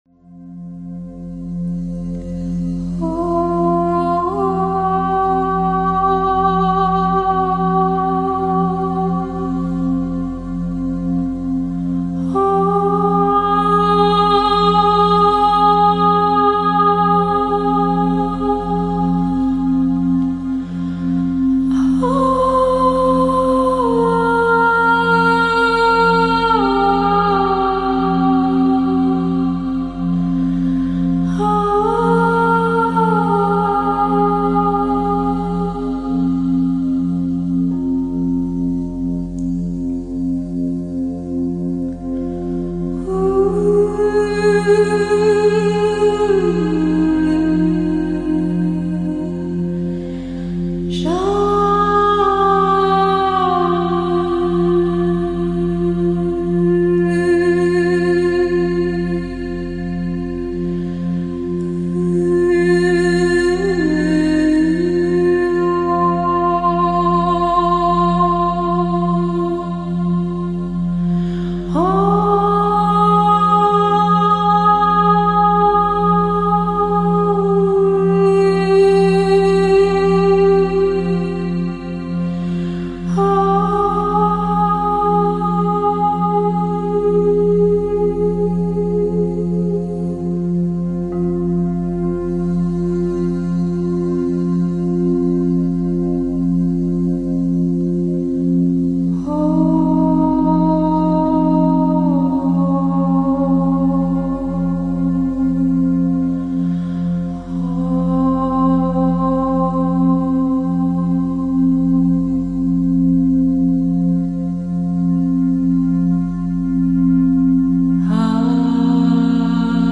Musica per accompagnare le sessioni di Rilassamento online o da ascoltare quando si vuole